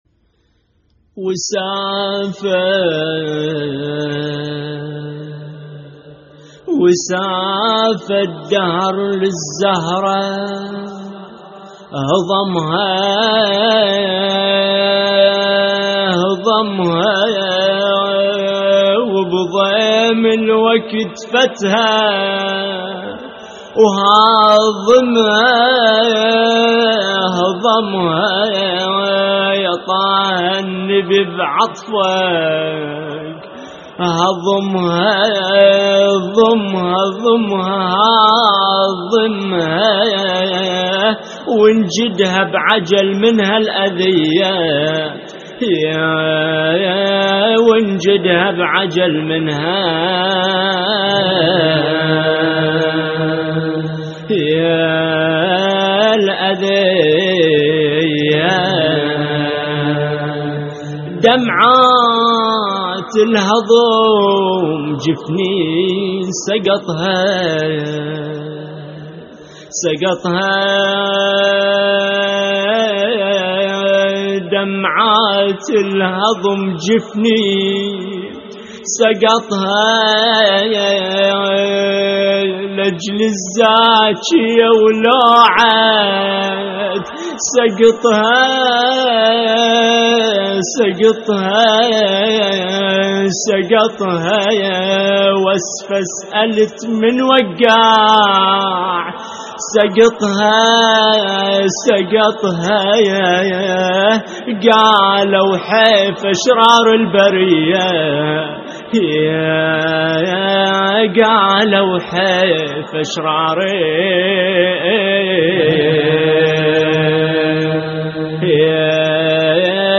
وسافه الدهر للزهرة هضمها(أبوذيات) - استديو